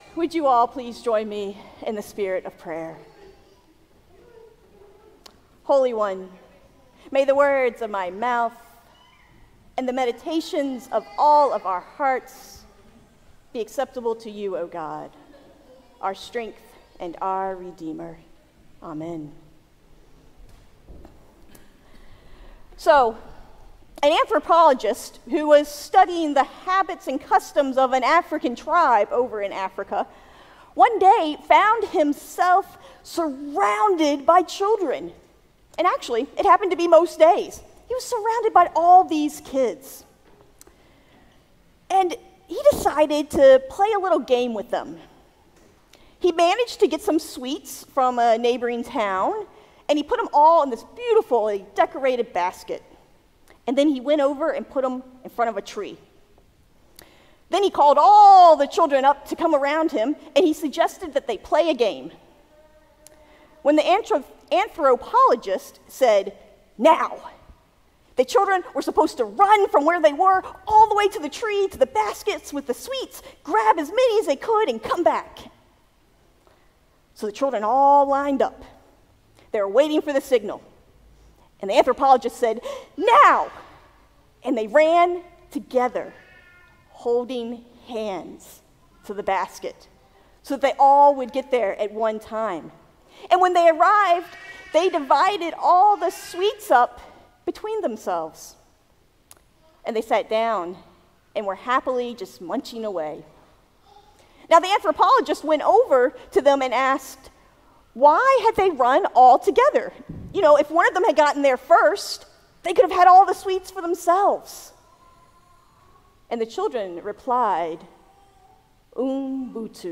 Sermon on the Fruits of the Spirit from Galatians 5.